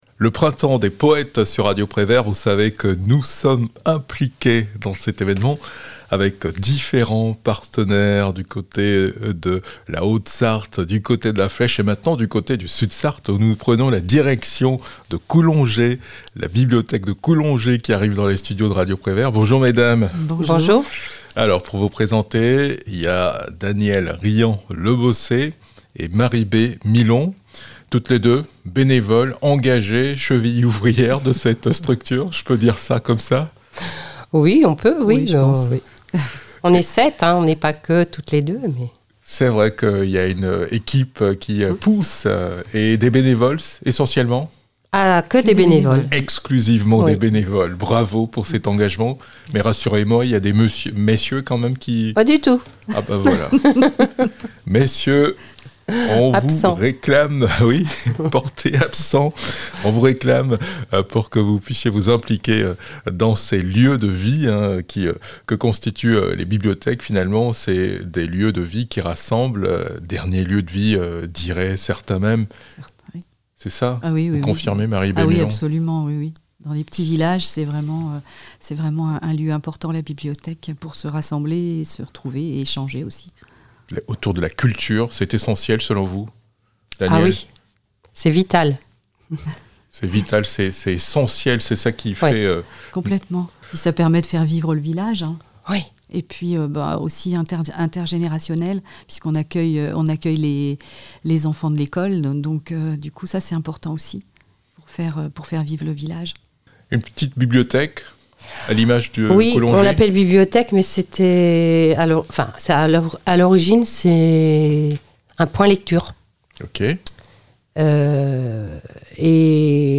Poésies chantées à la bibliothèque de Coulongé